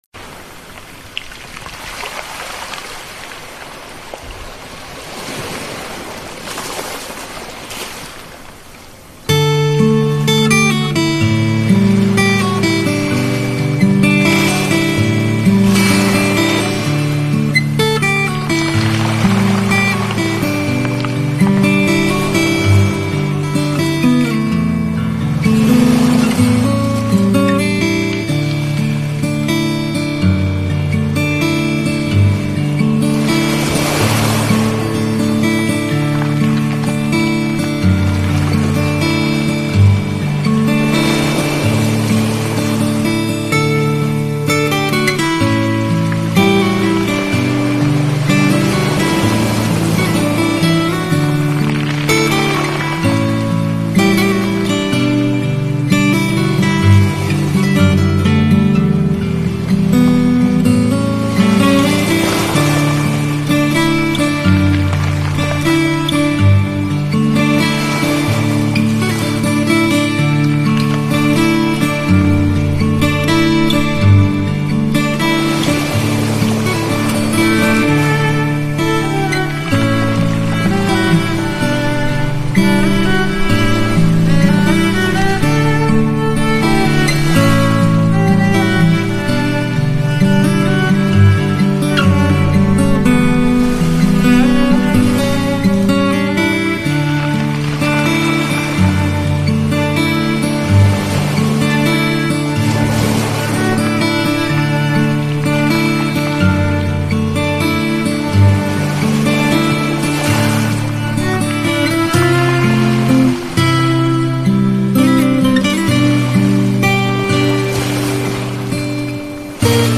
这优美的旋律让你仿佛置身于大自然中。动听自然柔美的海洋之声，让你在大海母亲的呵护声中静静舒缓你的心情。